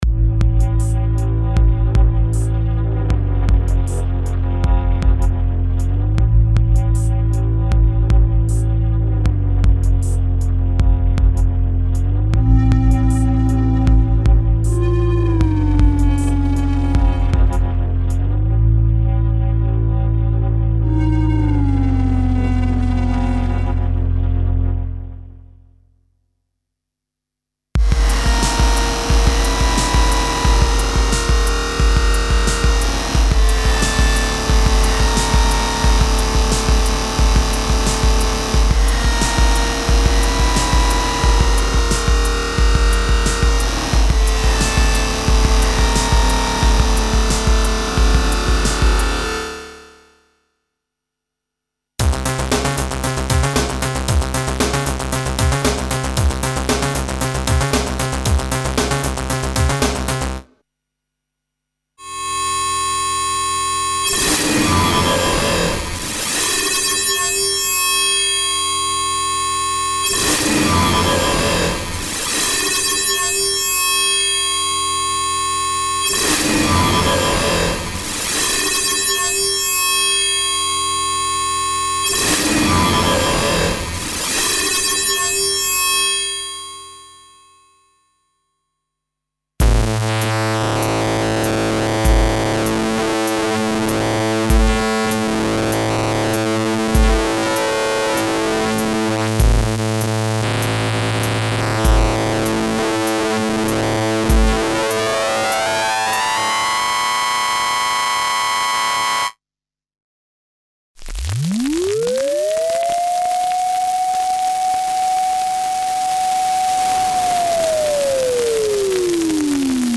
Special collection dedicated to all fans of hard electronica.
This soundware bundle contains aggressive synth basses, killer syncs, raw and gritty pads, lo-fi seq. programs and "toxic" effects, created to take advantage of the special controller settings for internal DSP modulations (e.g. filter, pitch, shaper, wrap, distortion, etc.).
This is an essential soundware for electronic underground music styles (e.g. Industrial, EBM, Dark Wave, Noise, etc.).
Info: All original K:Works sound programs use internal Kurzweil K2600 ROM samples exclusively, there are no external samples used.